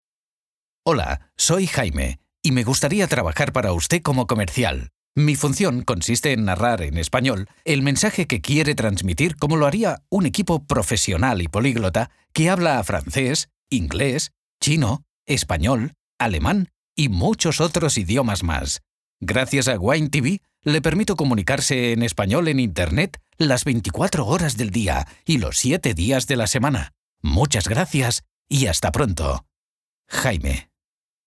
Male
Adult (30-50)
As a voiceover artist, he covers a wide range of styles, spanning the voice spectrum from casual to corporate, conversational to narrator. He can be reassuring and soft as well as lighthearted and funny.
Narration
E-Learning